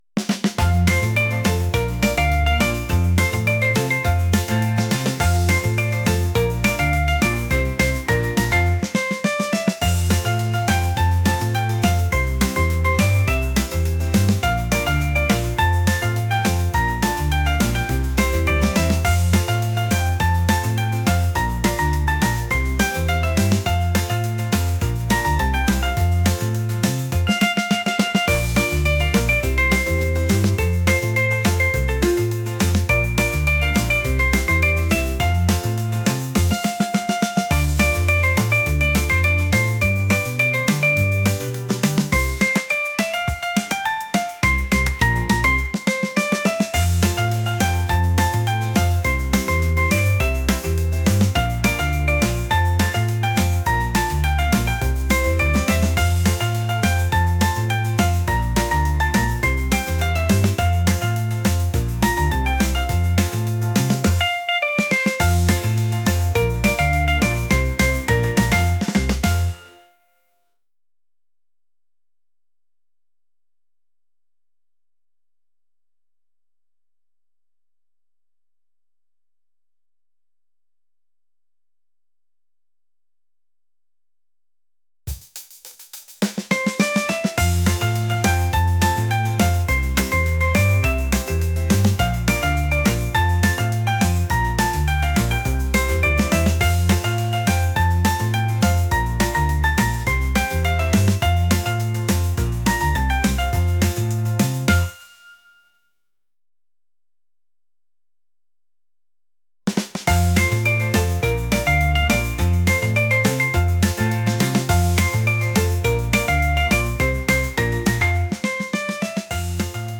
pop | upbeat